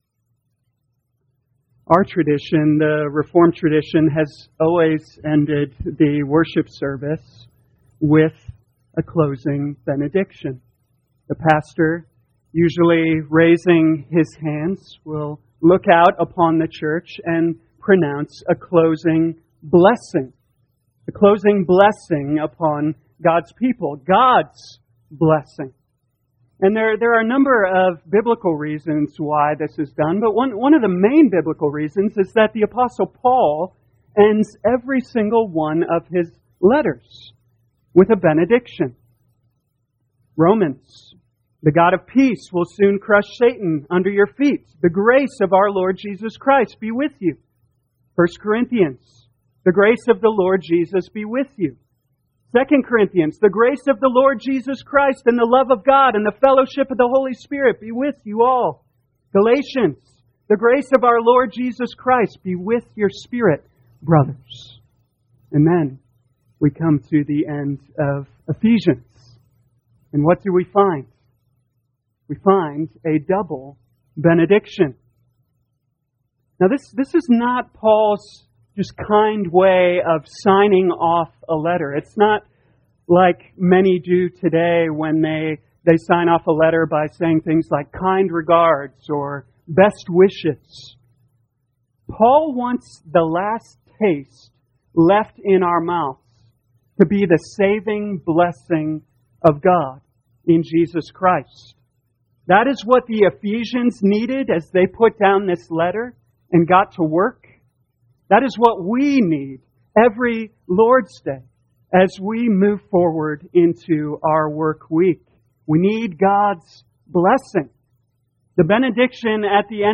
2021 Ephesians Evening Service Download